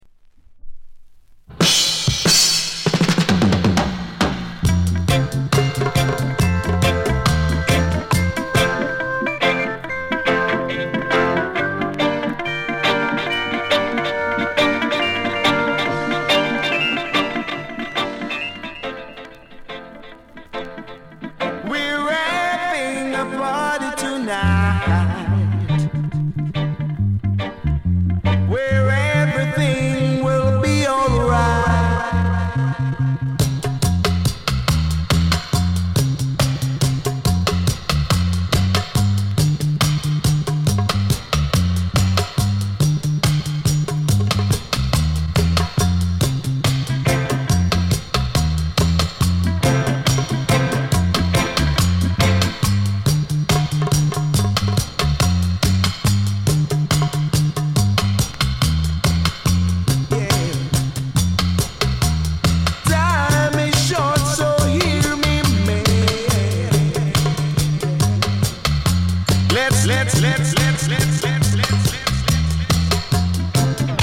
UK・英 7inch/45s
類別 雷鬼